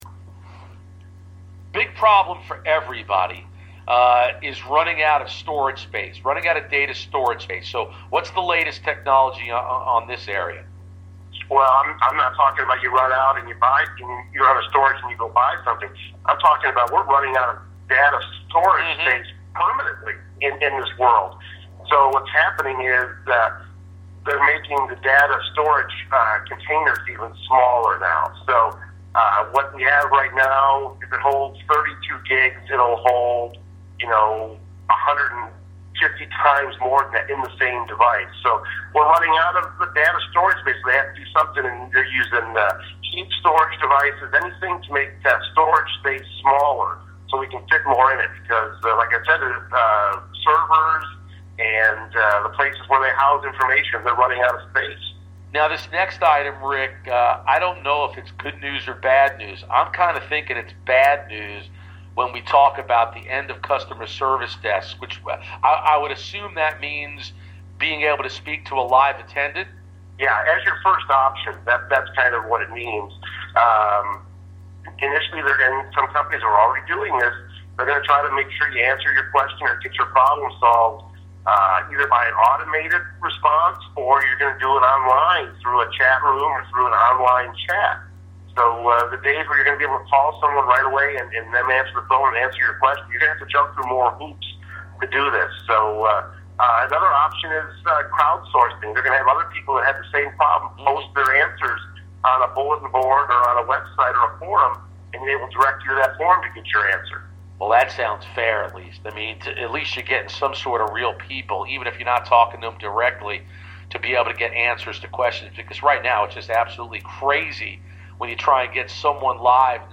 Wireless Wednesday for 1/14/15 on 92.9 The Game Part 2